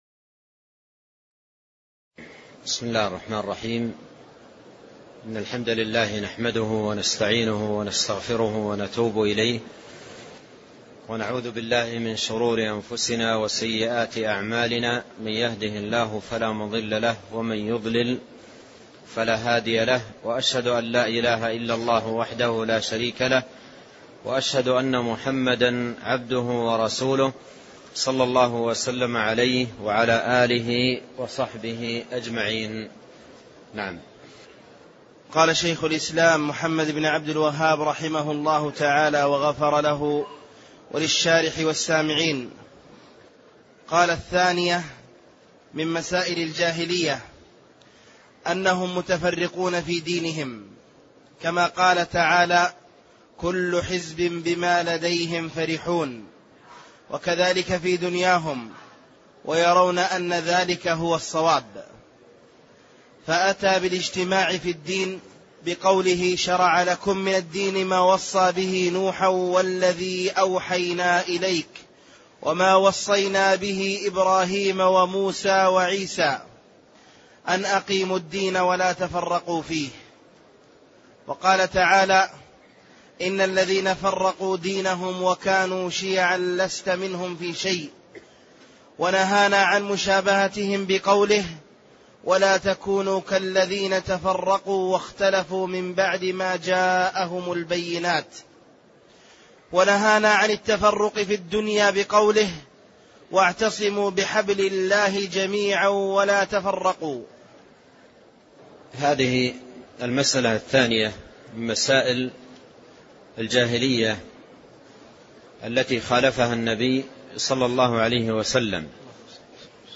الدرس 2 الثانية : أنهم متفرقون في دينهم